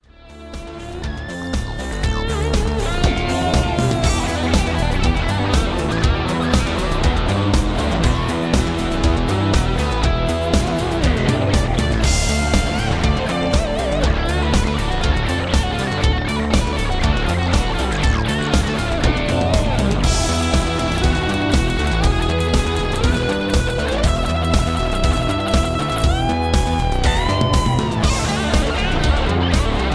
Lead guitar rock tune.